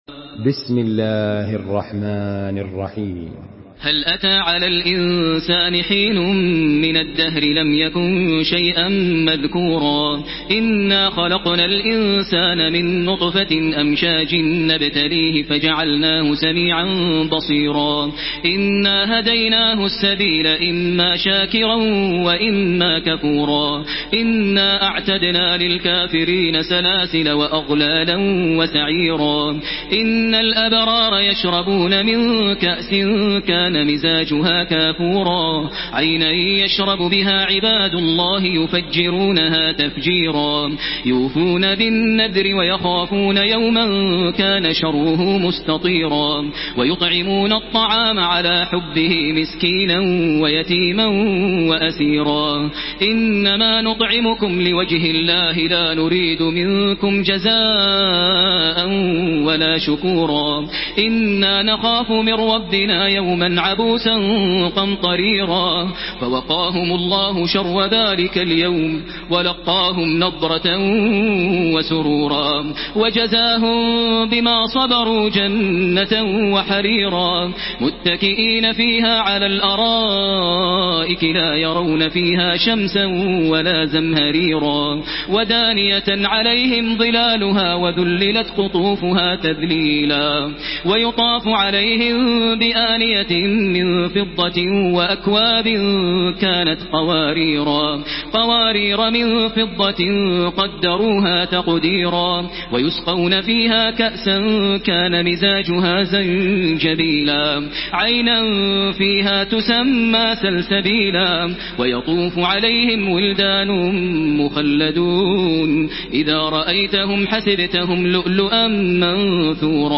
Surah আল-ইনসান MP3 by Makkah Taraweeh 1433 in Hafs An Asim narration.
Murattal